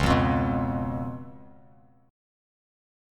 Db7 Chord
Listen to Db7 strummed